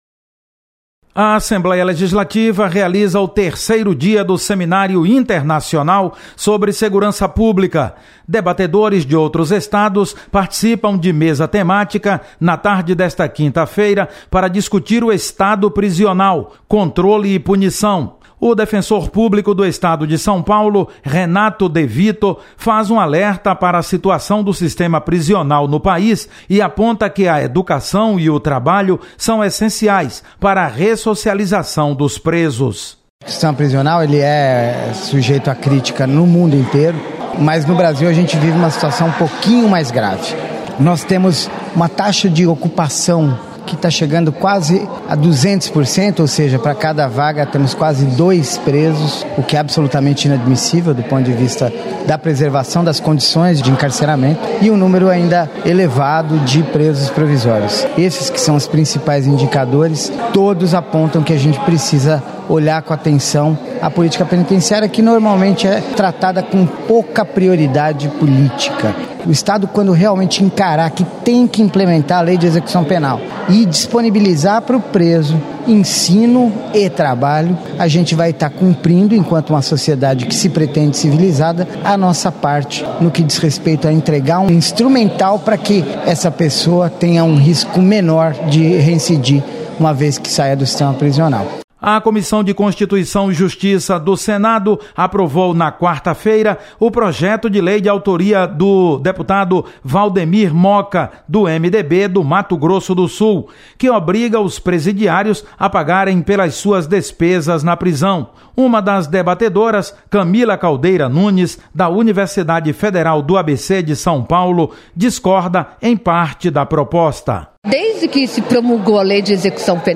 Seminário debate o Estado Prisional, Controle e Punição na tarde desta quinta-feira. Repórter